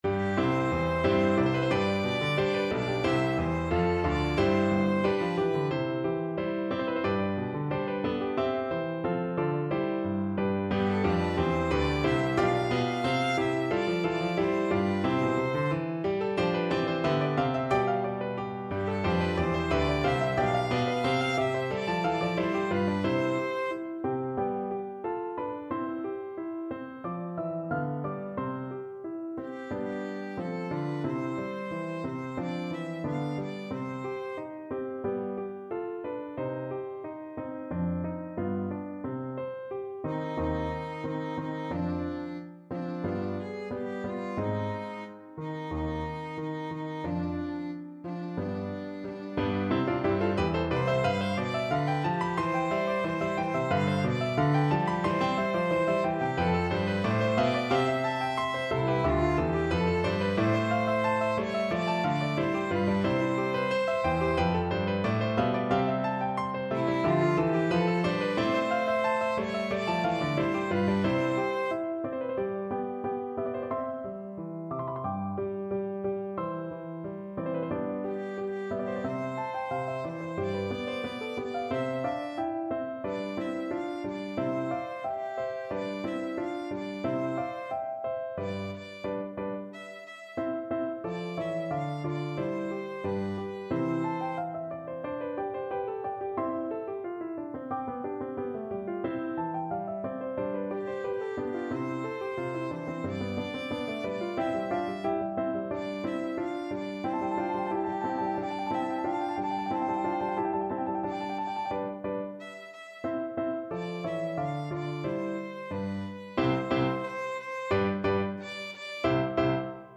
Classical Haydn, Franz Josef The Heavens are Telling from The Creation (Die Schöpfung) Violin version
C major (Sounding Pitch) (View more C major Music for Violin )
Allegro =180 (View more music marked Allegro)
4/4 (View more 4/4 Music)
C5-F6
Violin  (View more Easy Violin Music)
Classical (View more Classical Violin Music)
haydn_heavens_telling_VLN.mp3